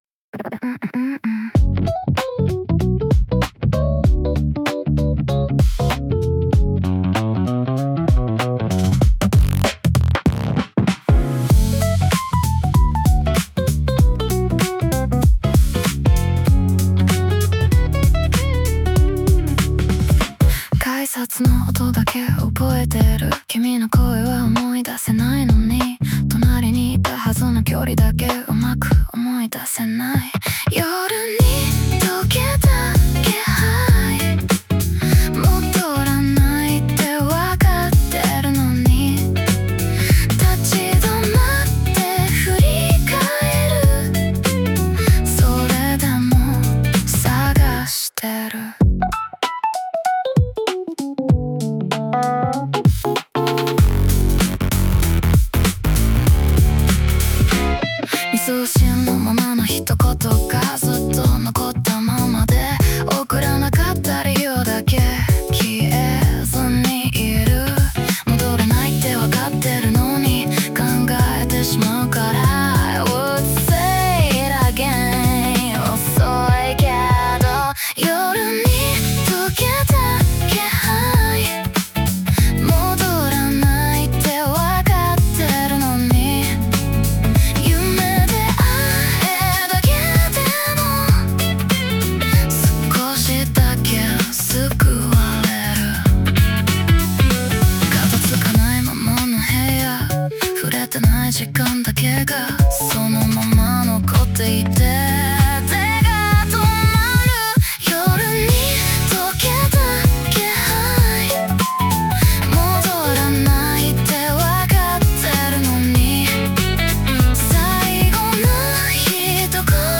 女性ボーカル
イメージ：おしゃれ,鳴きのギター,女性ボーカル,ローファイ,ブーム・バップ